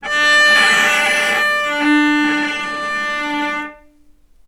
vc_sp-D4-ff.AIF